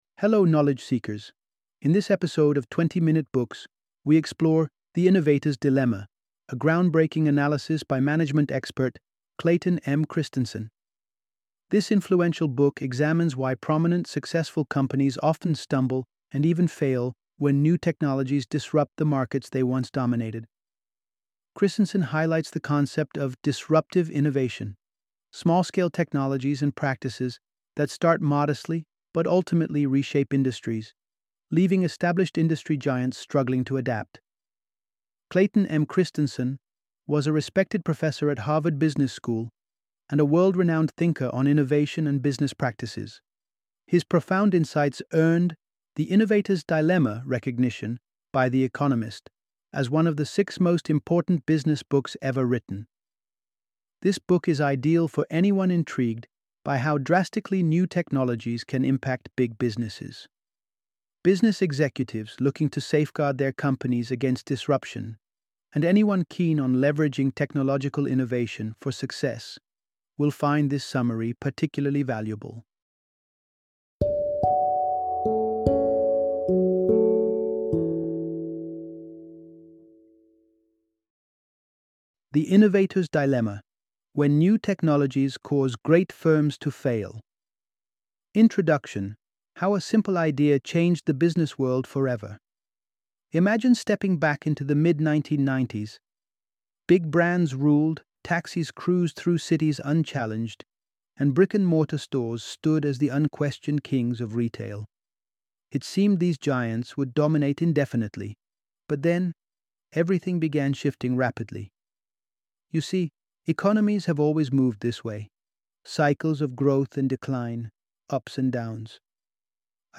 The Innovator’s Dilemma - Audiobook Summary